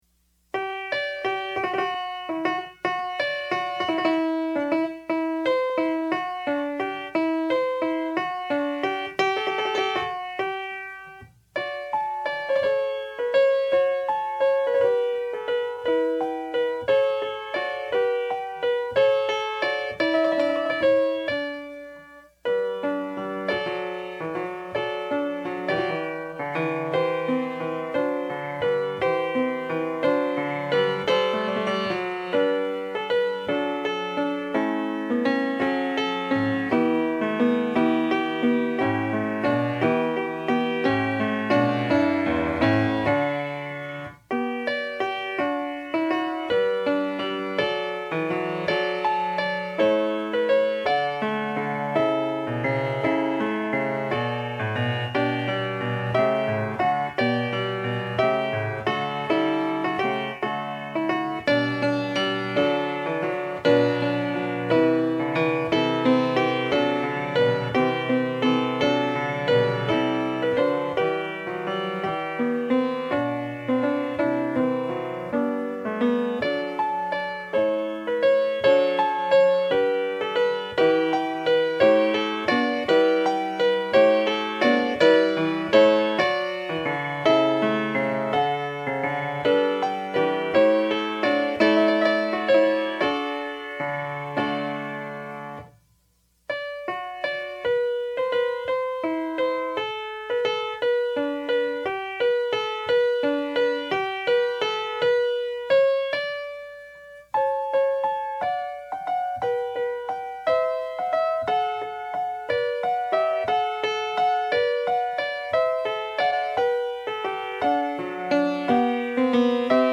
DIGITAL SHEET MUSIC - PIANO SOLO
Sacred Music, Piano Solo, Prelude, Interlude, & Offertory